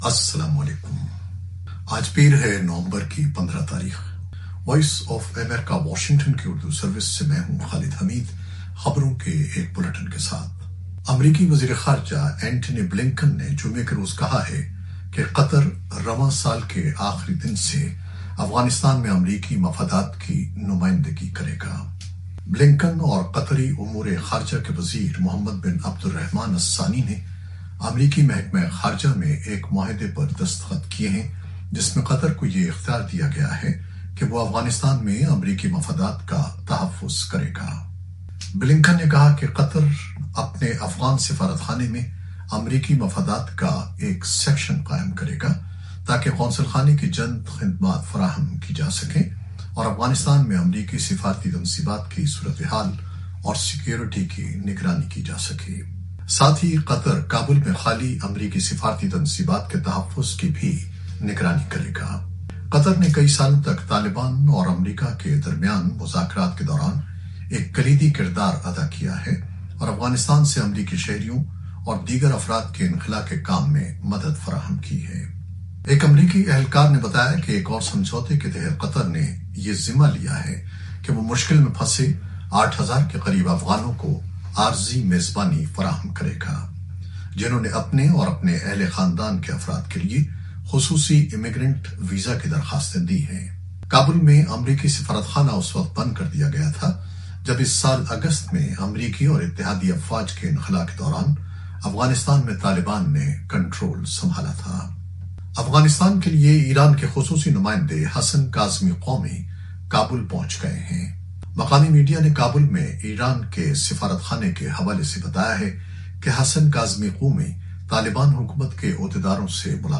نیوز بلیٹن 2021-15-11